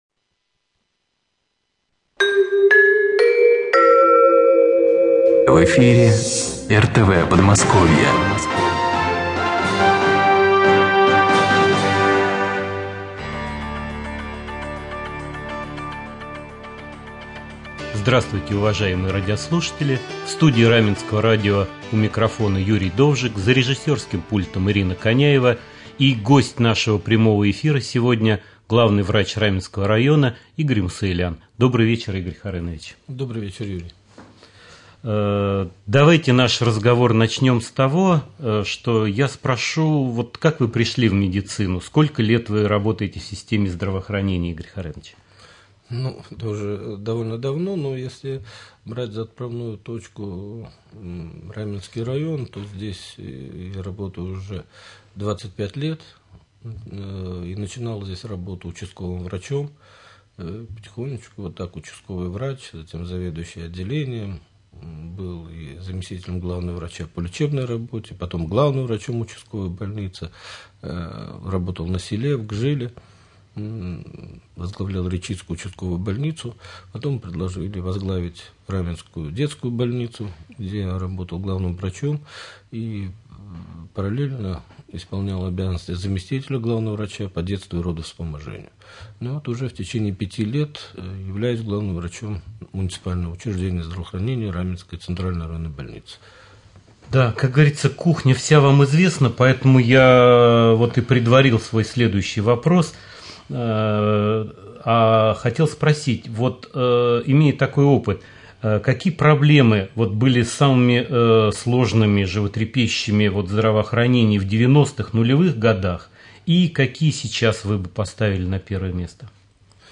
в прямом эфире Раменского радио
ответит на вопросы нашего корреспондента и радиослушателей.